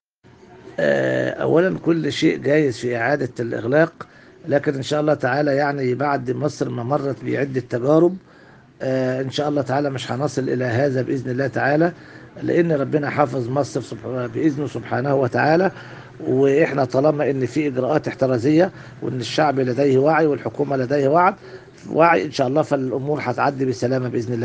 الحوار الصحفي التالي